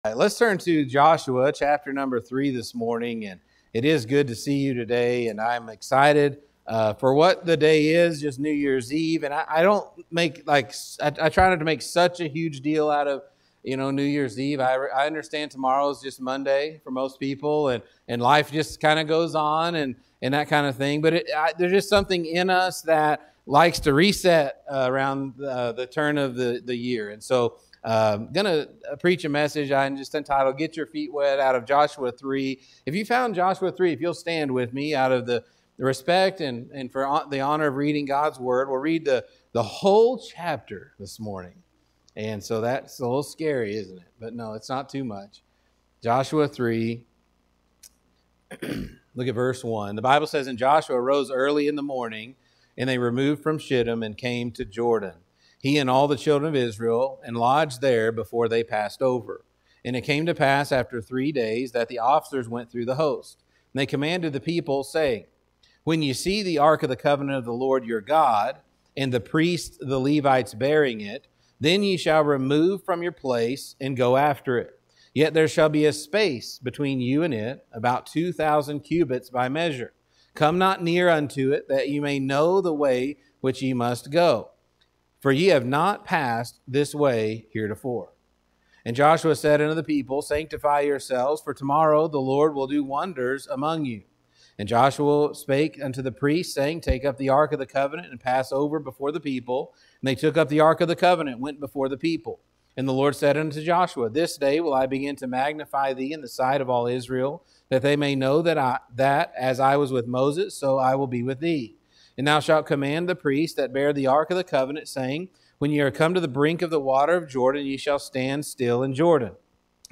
Get-your-feet-wet-Sunday-AM.mp3